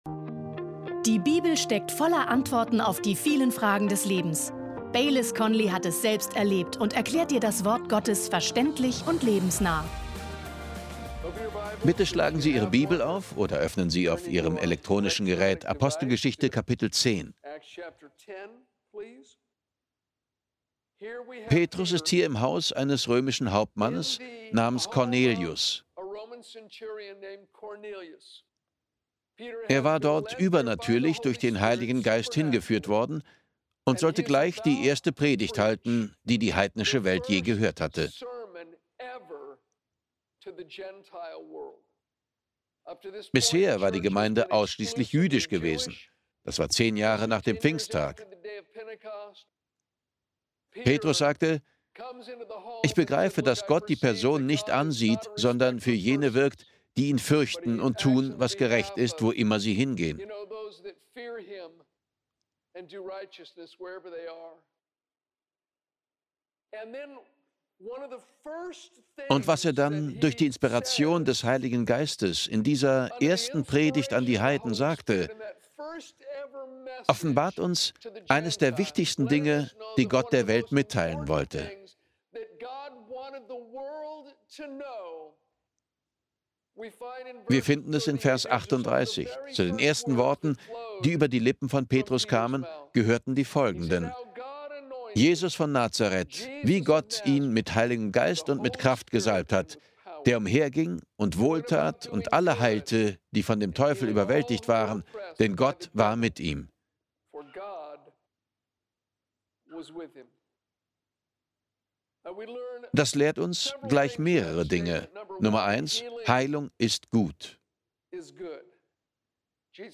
Mehr dazu in der Predigt!